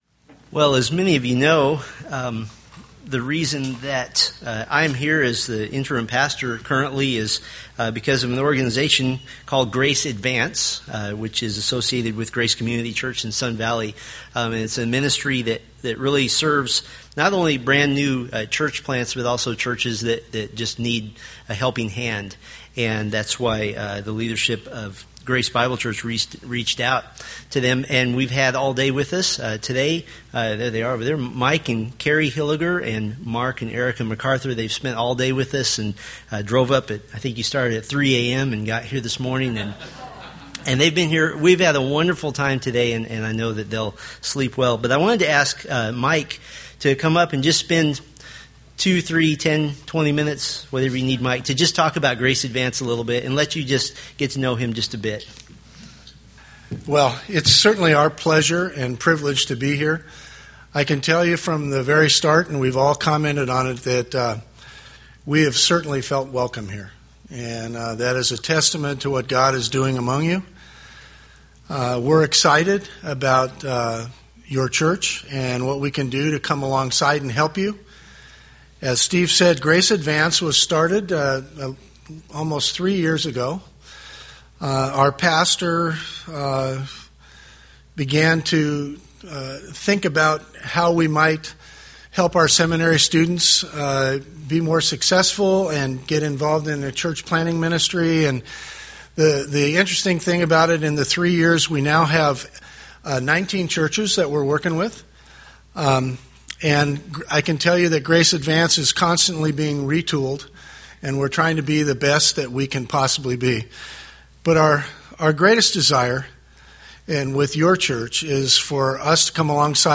Philippians Sermon Series